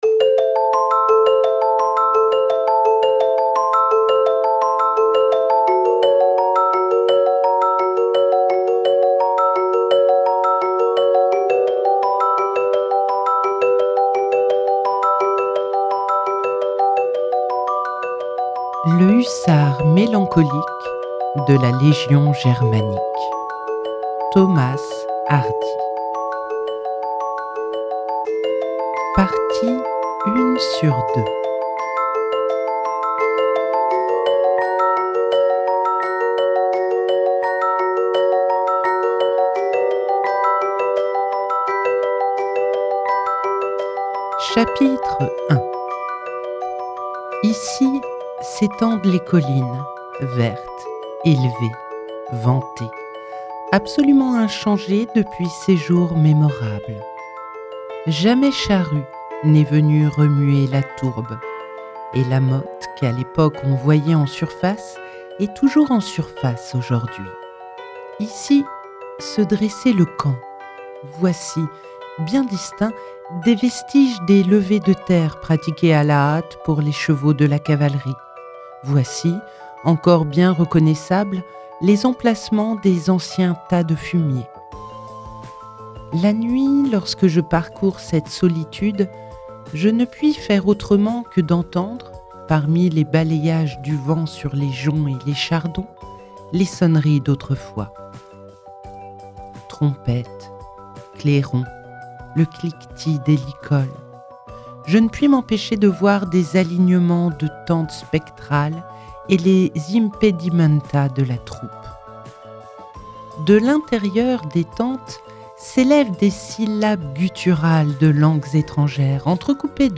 🎧 Le Hussard mélancolique de la Légion germanique – Thomas Hardy - Radiobook